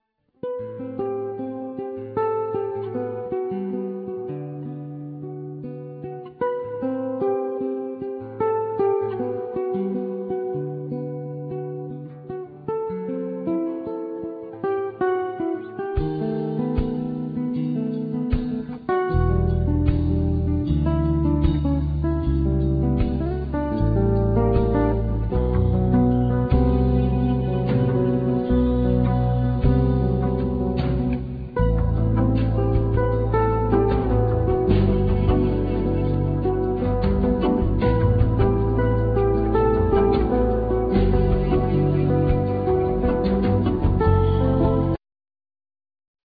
Banjo,Guitars,Piano
Double Bass
Vocals
Violin
Accordion
Drums